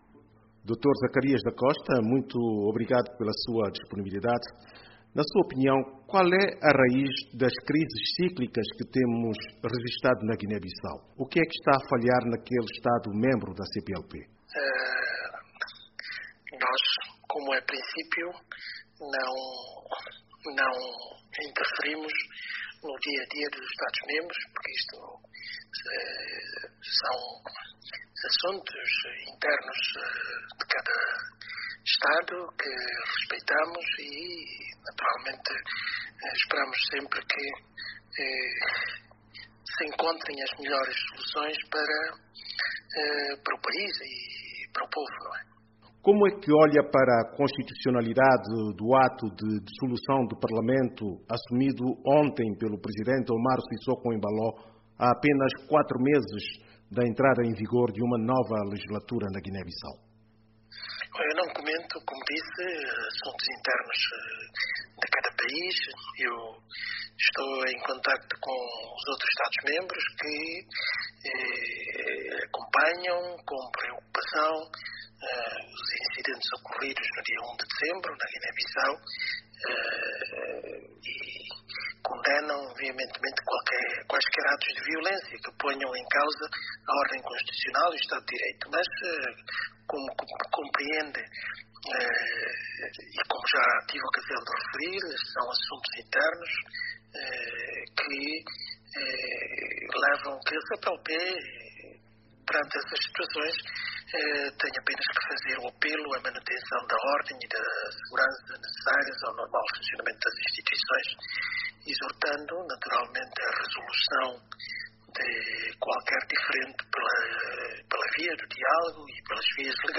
CPLP pondera enviar missão à Guiné-Bissau - Entrevista com o Sec Exe. Zacarias da Costa